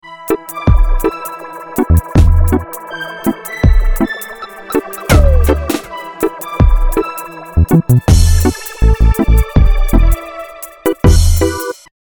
恐怖怪异的悬念
描述：这是我所做的一个声音，可能会对某种令人毛骨悚然的悬念有所帮助。用Sony HDRPJ260V录制，然后用Audacity编辑。
Tag: 险恶 建立 怪兽 恐怖 可怕 张力 怪异 闹鬼 悬念 幽灵